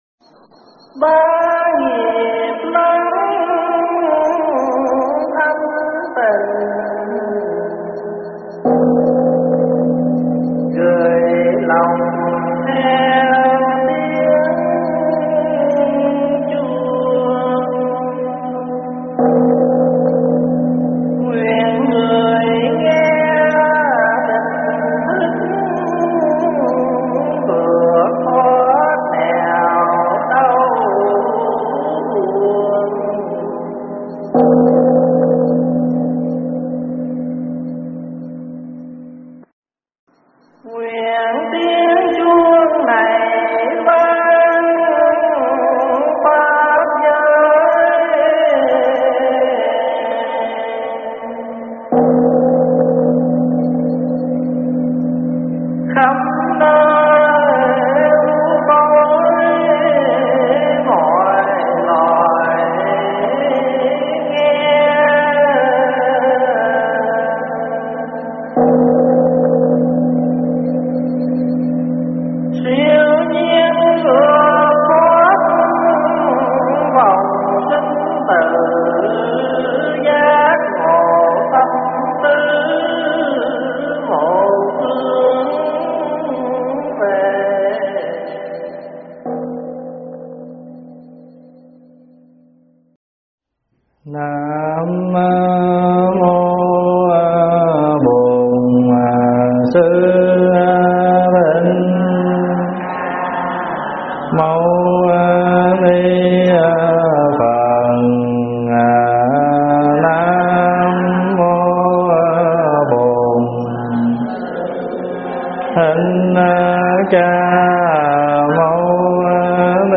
Thuyết pháp Bồ Tát Tại Gia 80 (kinh ưu bà tắc)
giảng tại tu viện Trúc Lâm